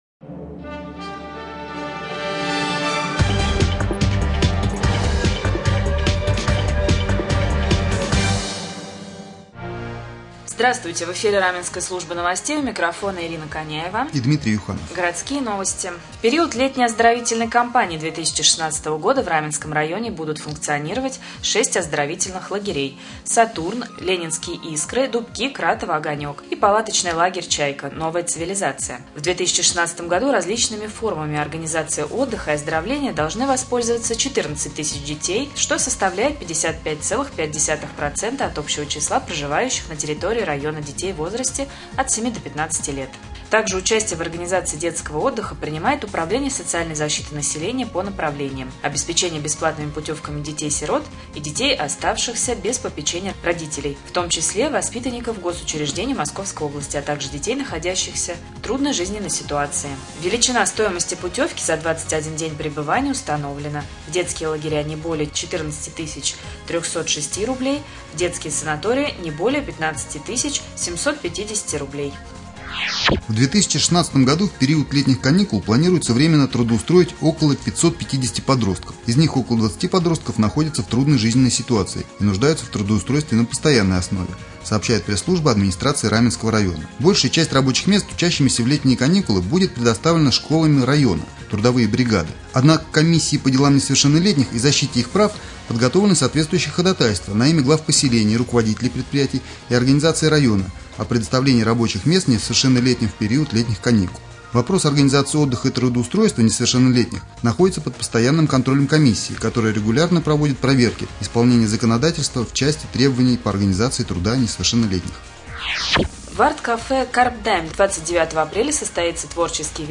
1. Новости 2. В прямом эфире глава с.п.Верейское С.В.Чистюхин